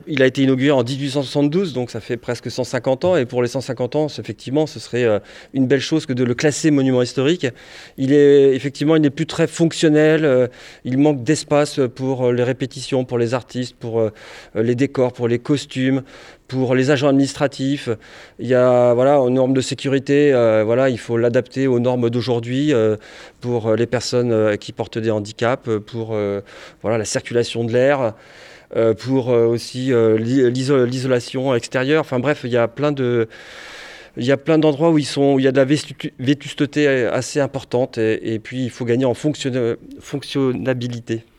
On vous laisse écouter Christophe Dupin, l’adjoint à la Culture et à l’éducation populaire, qui nous détaille son plan.